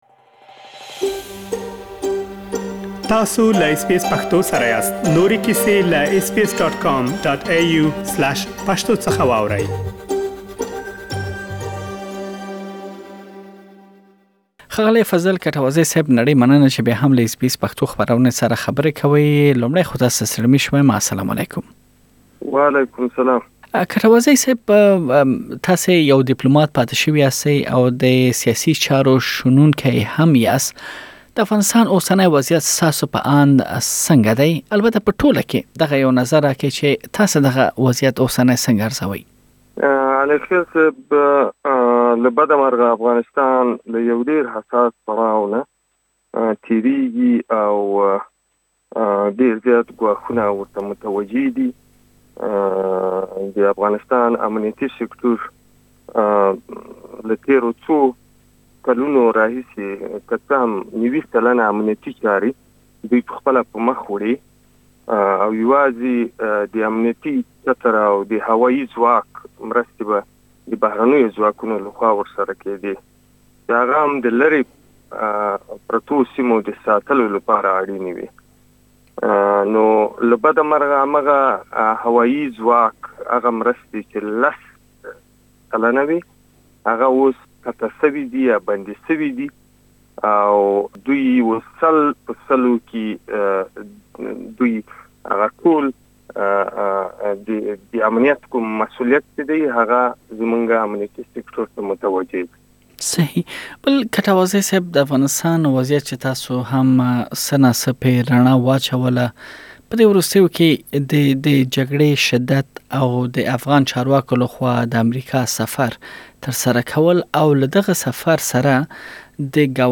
تاسې دا او نور ډير مهم مطالب په بشپړه مرکه کې واورئ.